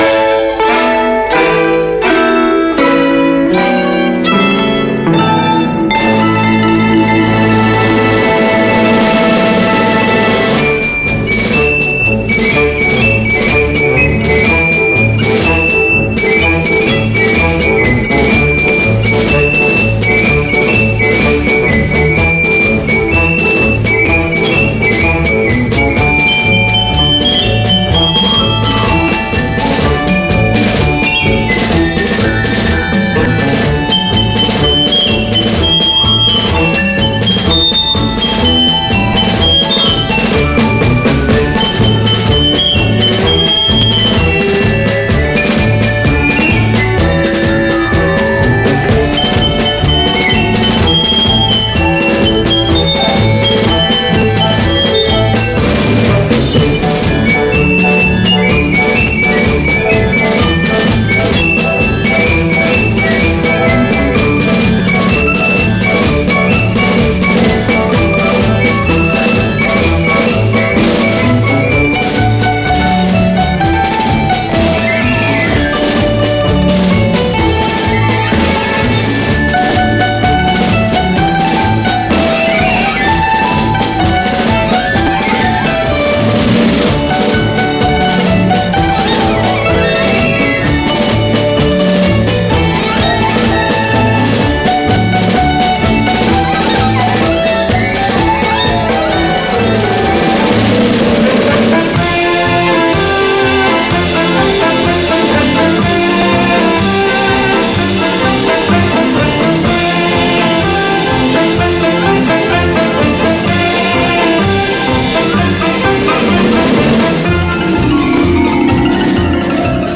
Mέτρο δύο τετάρτων εικόνα
Στο εμβατήριο, λοιπόν, οι χτύποι τονίζονται σταθερά ανά δύο.